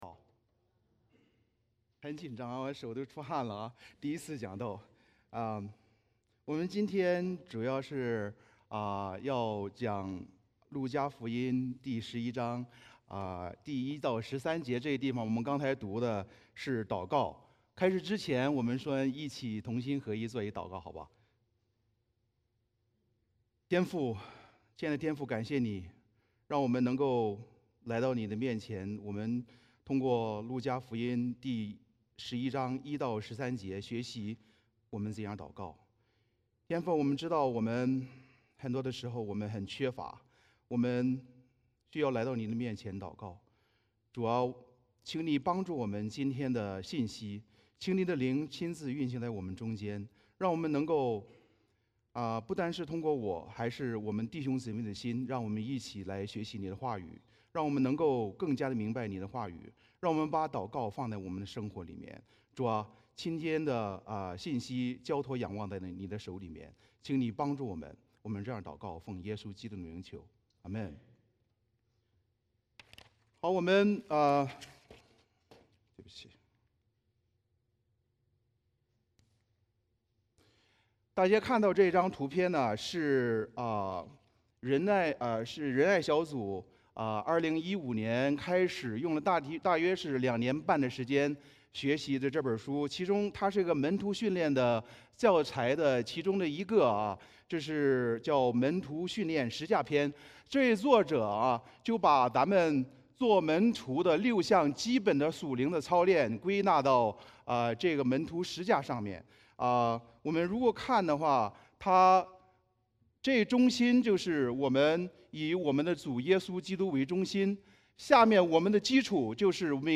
门徒的祷告 - Mandarin Ministry Congregation